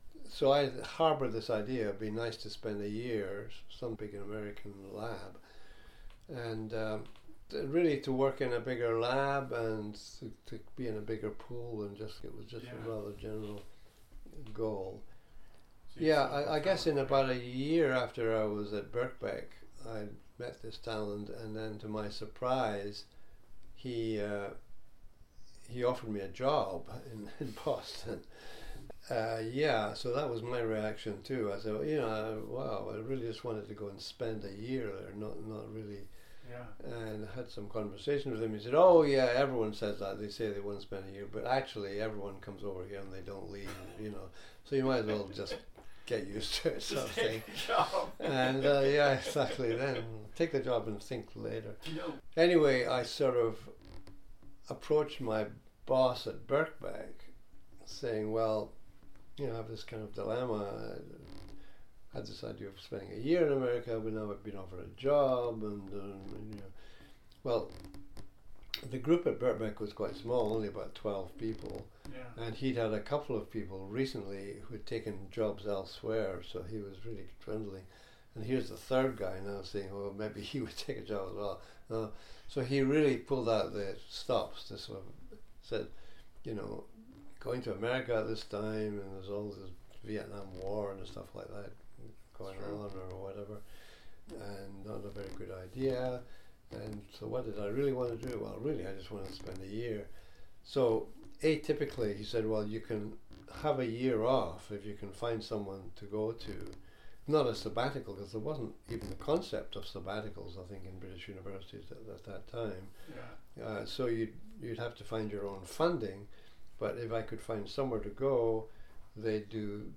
Not long after that visit to Toronto, Dr. Craik receives a phone call from Canada. He describes that here and the events to follow: